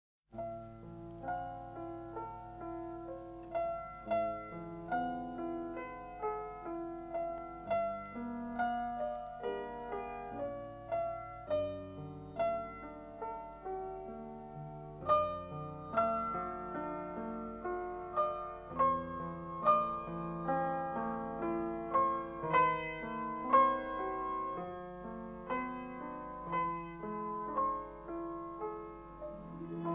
Жанр: Соундтрэки / Джаз